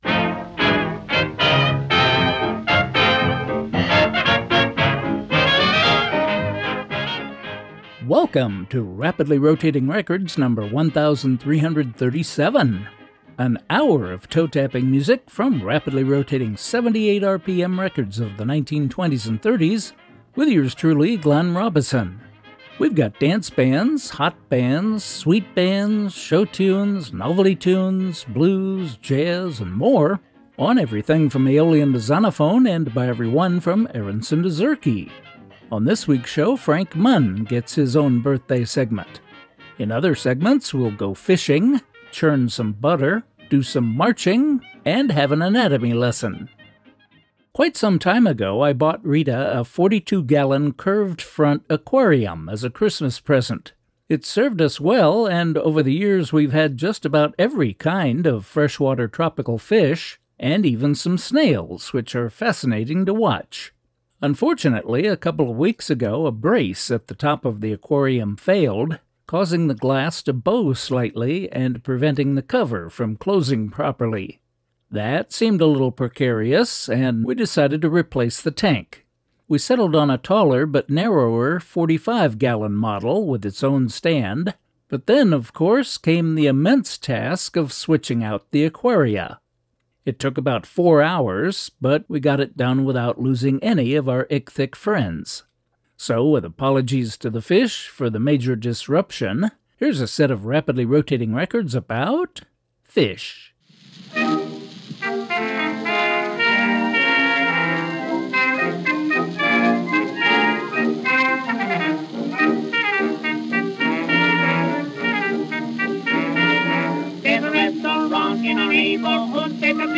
bringing you vintage music to which you can’t not tap your toes, from rapidly rotating 78 RPM records of the 1920s and ’30s.